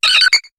Cri de Togetic dans Pokémon HOME.